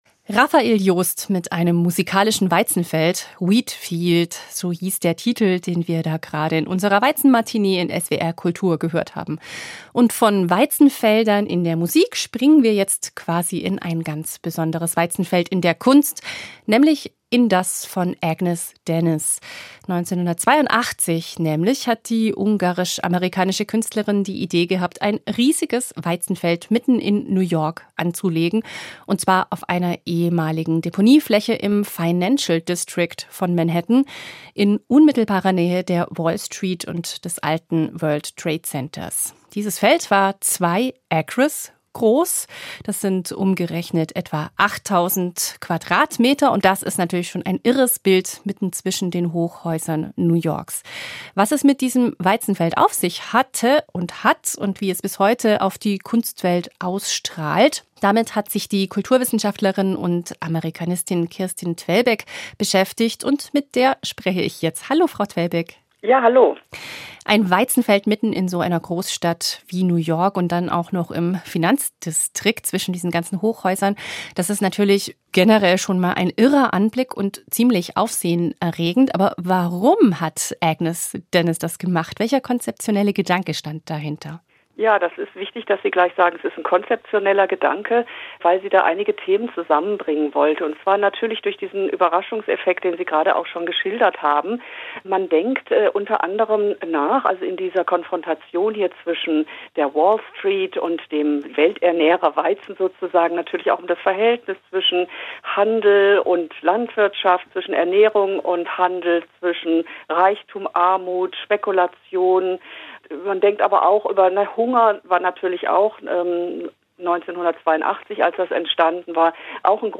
Gespräch
Ein Gespräch über die politische Kraft von Kunst und Weizen.